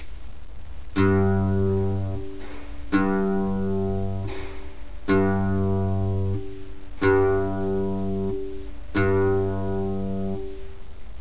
El sonido de cada cuerda afinada deberia de sonar asi:
Tercera(Sol)
afinacion_sol.wav